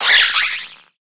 Cri de Morpeko Mode Rassasié dans Pokémon HOME.
Cri_0877_Rassasié_HOME.ogg